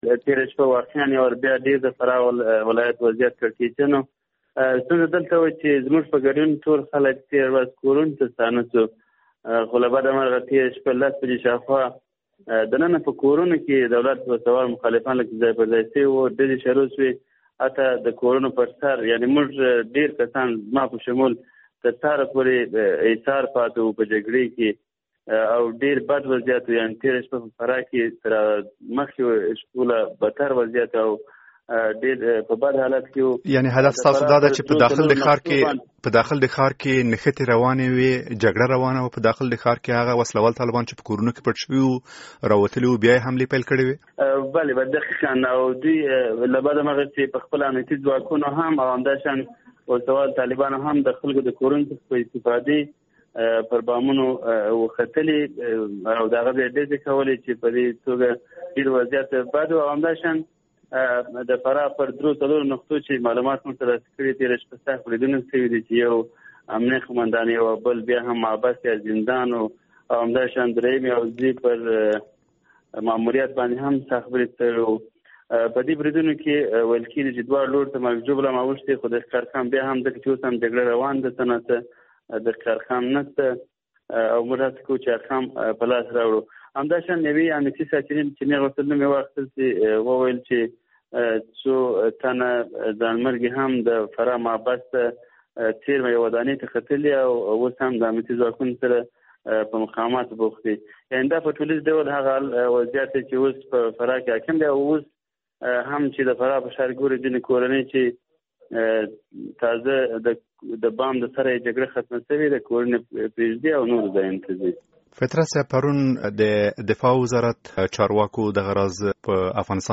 ژوندی راپور